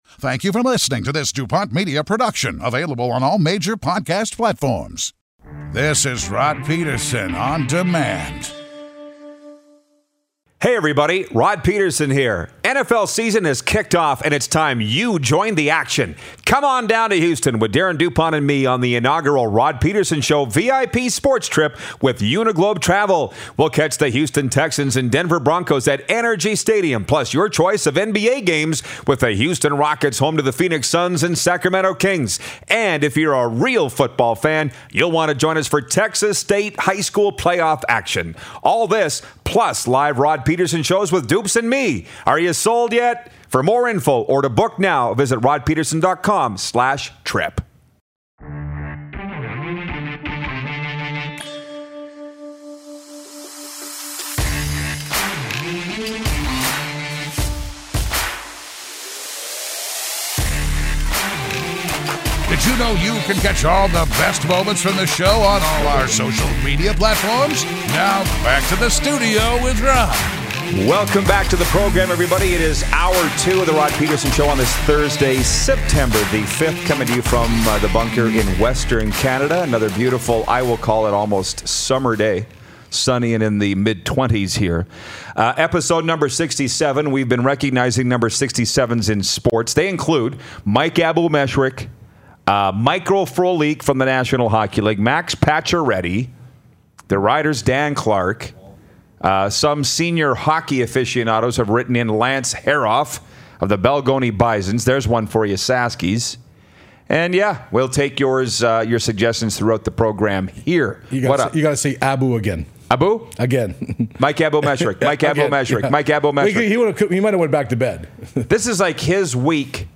takes a seat in studio
Blue Bombers Legend Crhis Walby calls in as well!